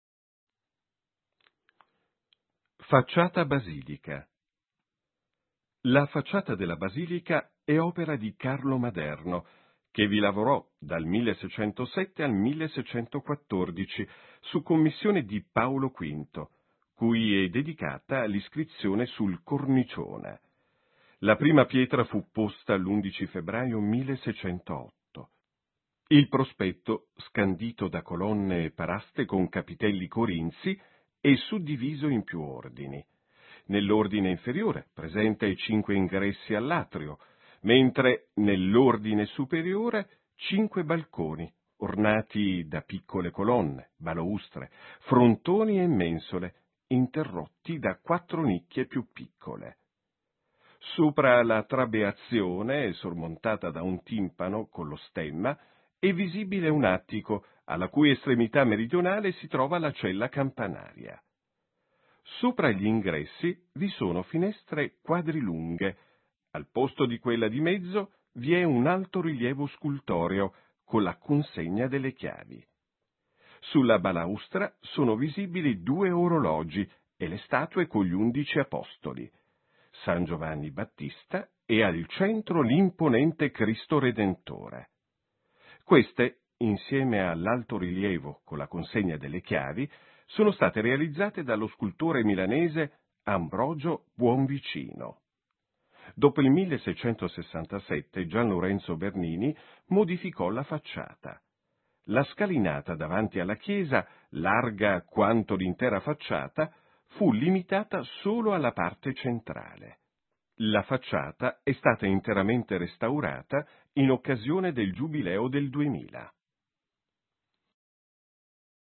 frate-it-facciata-basilica.mp3